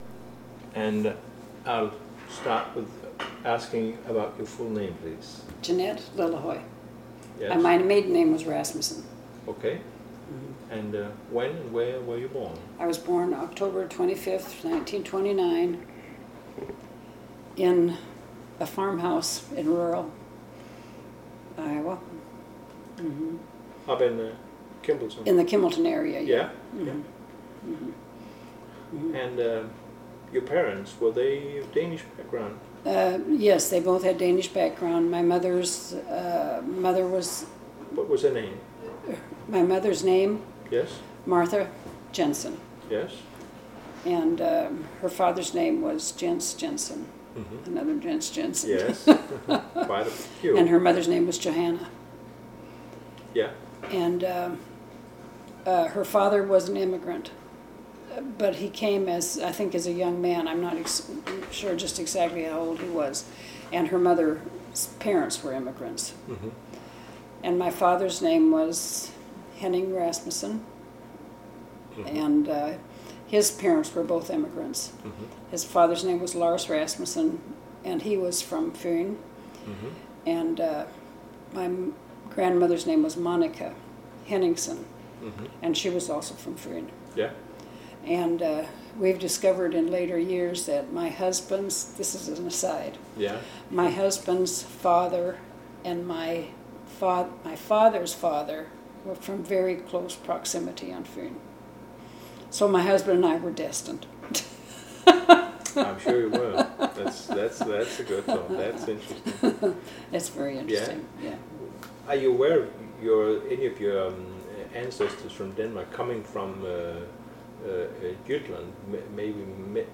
Audio file of interview